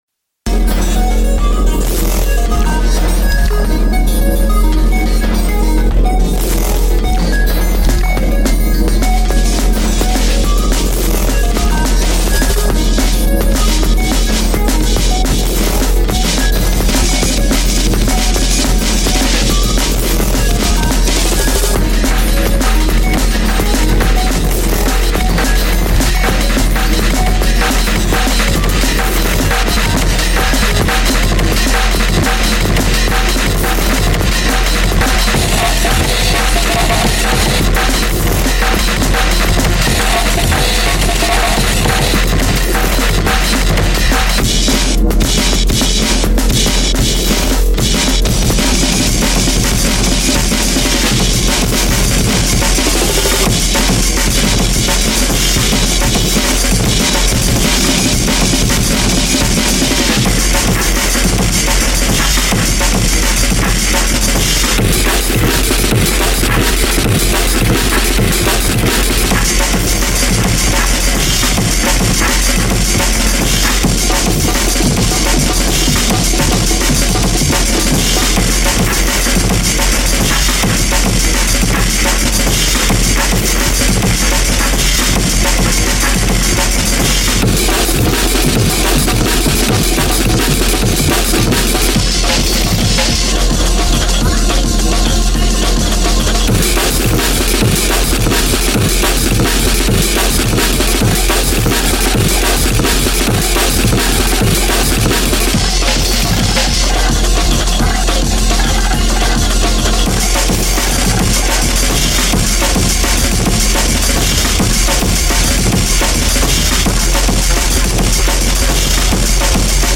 dnb,